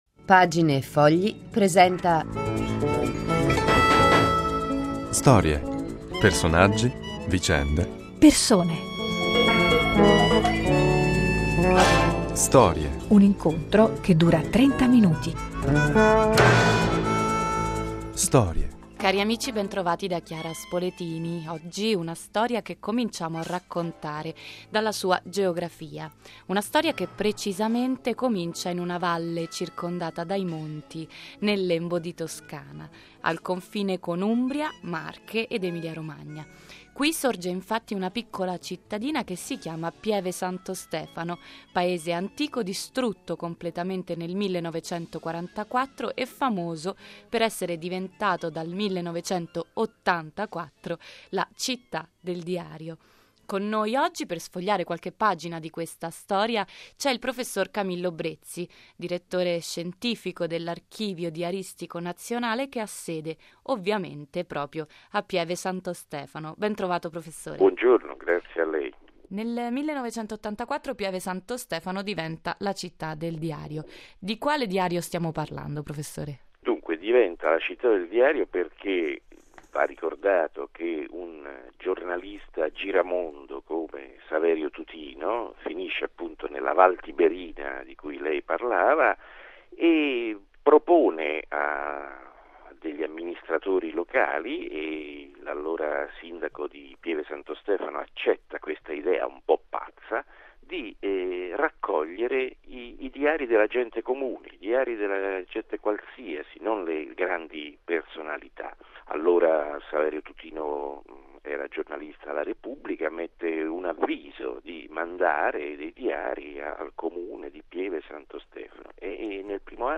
ai microfoni